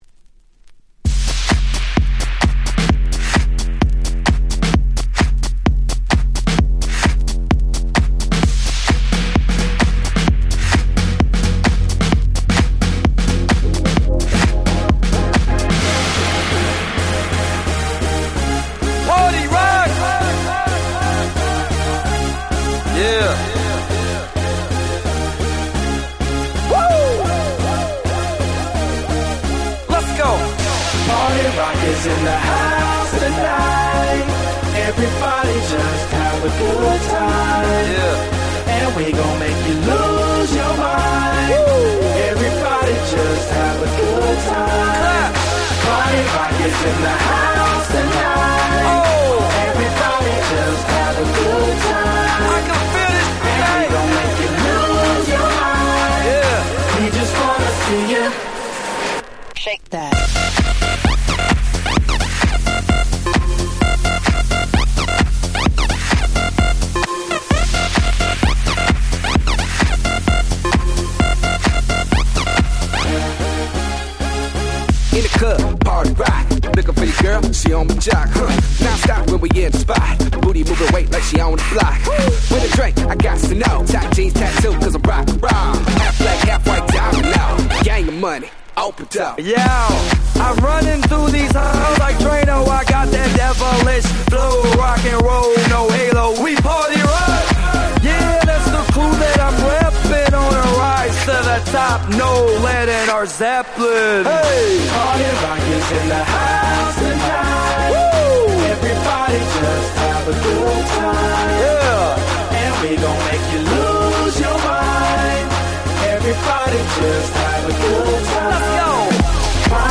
11' Super Hit EDM !!